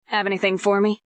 Update Female Voice boosting